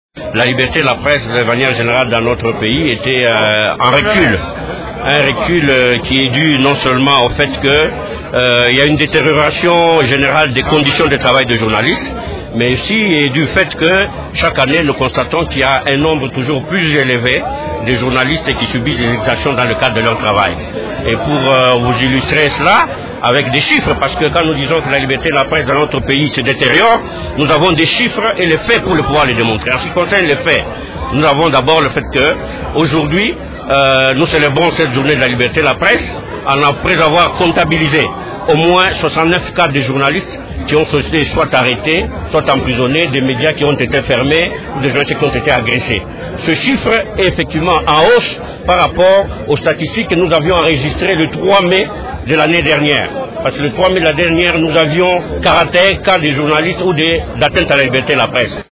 Le même appel a été lancé par Journaliste en danger (JED) lors de la cérémonie marquant la célébration de la liberté de la presse à Kinshasa.